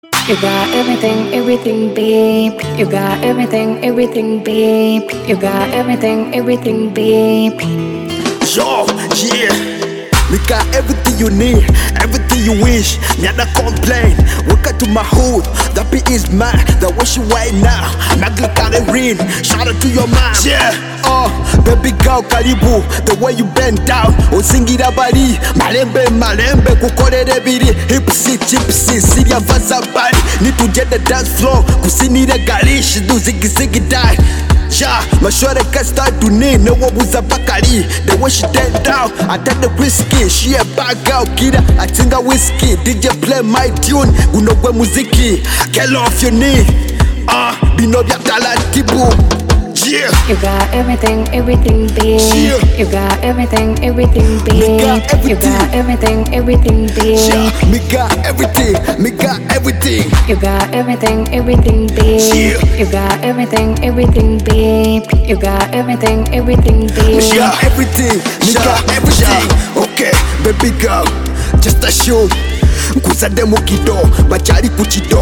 Genre: Afro Pop